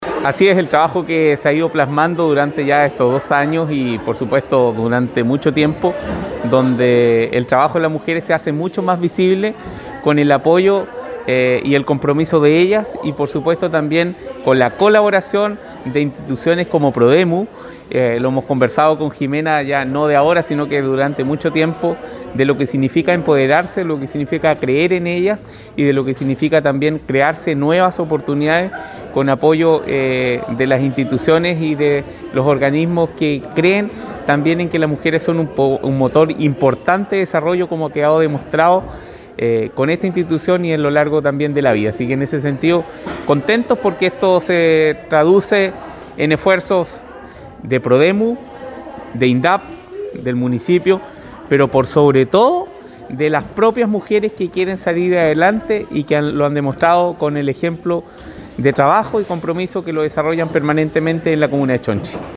Por su parte, el Alcalde Fernando Oyarzún resaltó el trabajo de las beneficiarias: